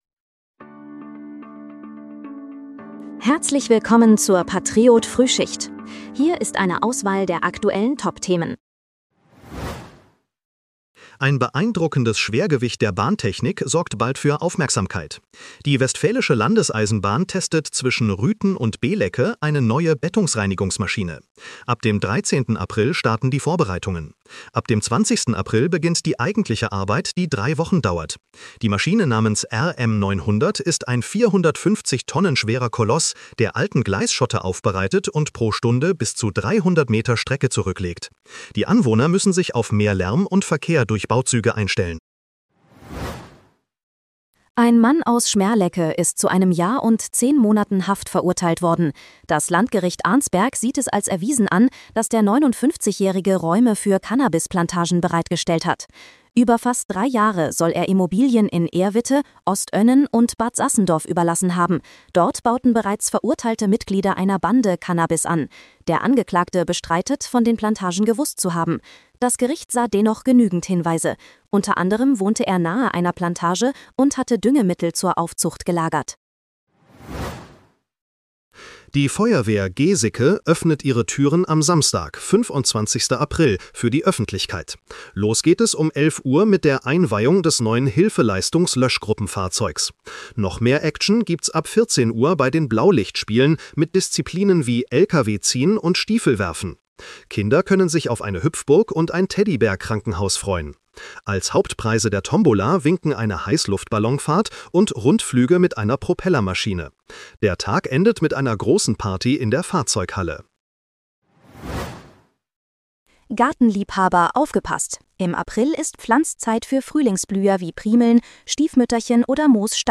Willkommen zur Patriot-Frühschicht. Dein morgendliches News-Update
mit Hilfe von Künstlicher Intelligenz.